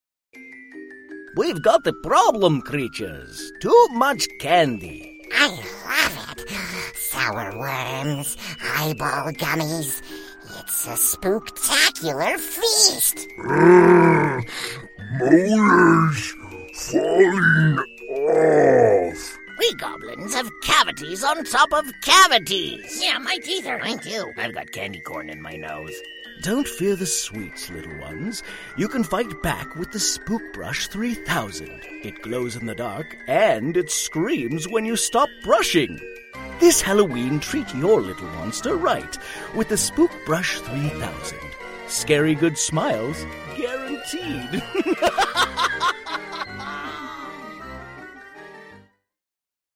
Male | Middle Age,Male | Young Adult in genders and Characters